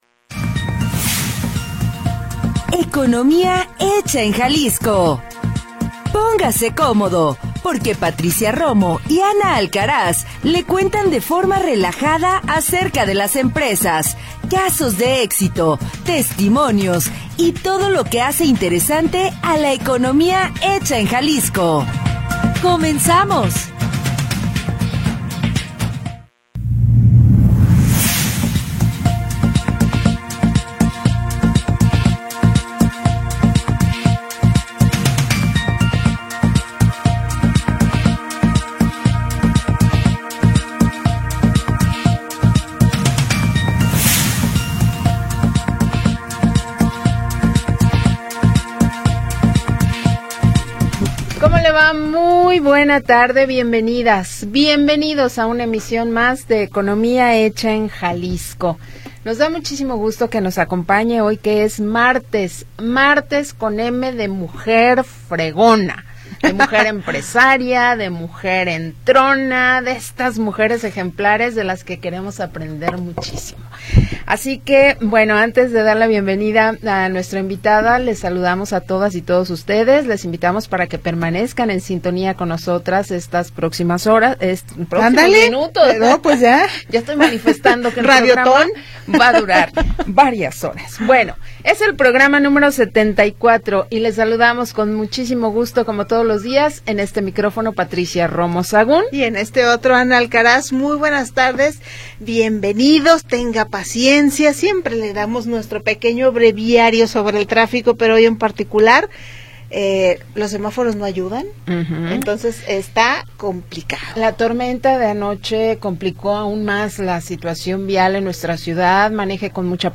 le cuentan de forma relajada